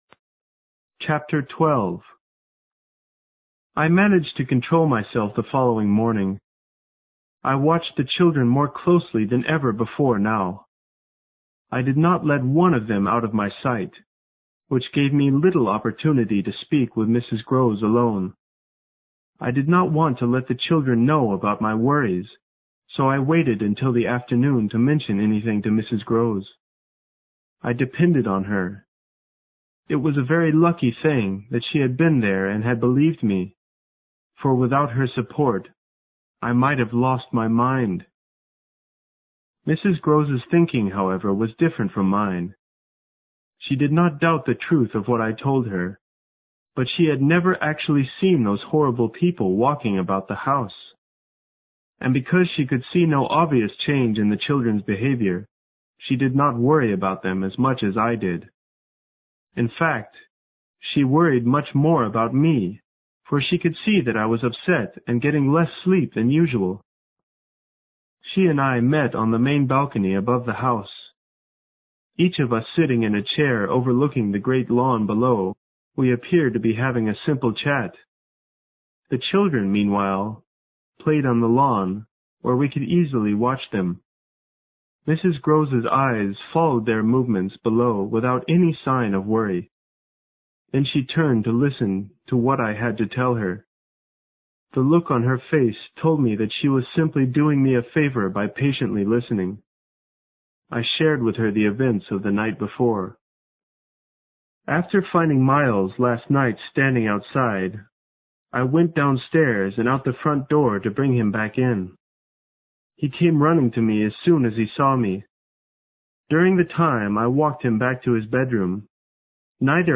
有声名著之螺丝在拧紧chapter12 听力文件下载—在线英语听力室